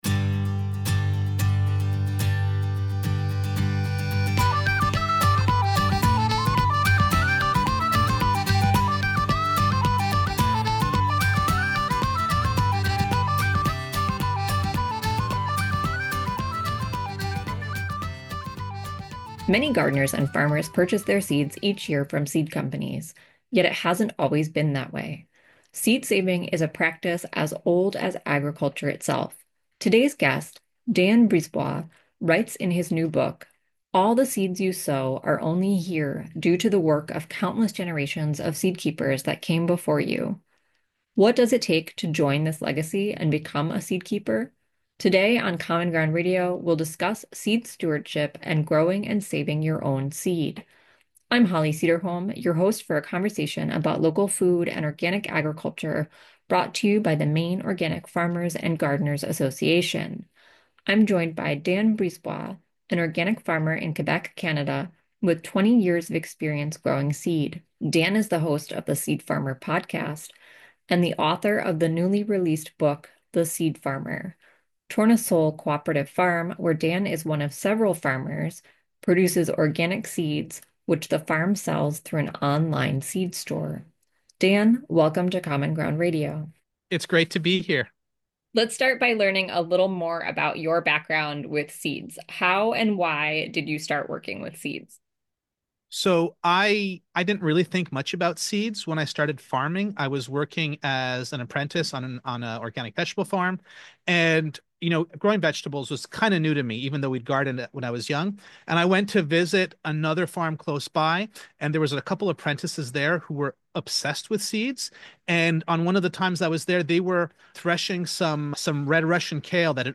Common Ground Radio is an hour-long discussion of local food and organic agriculture with people here in the state of Maine and beyond.